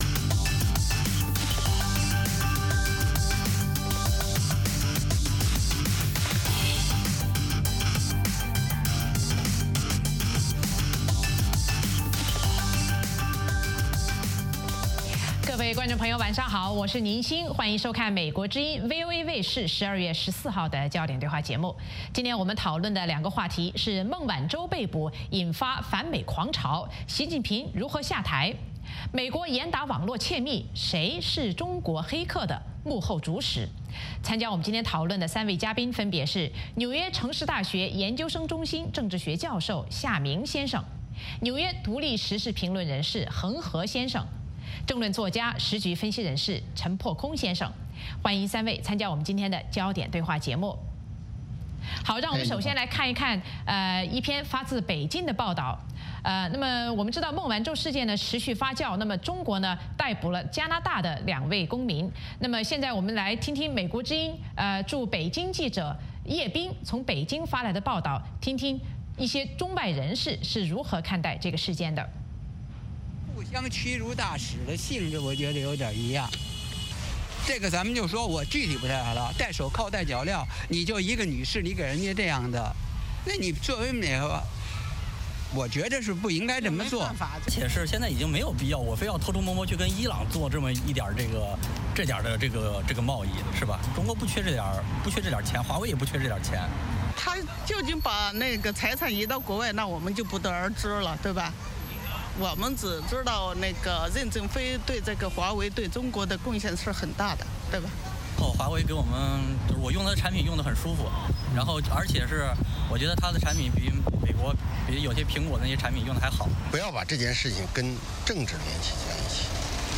美国之音中文广播于北京时间早上6－7点重播“焦点对话”节目。《焦点对话》节目追踪国际大事、聚焦时事热点。邀请多位嘉宾对新闻事件进行分析、解读和评论。或针锋相对、或侃侃而谈。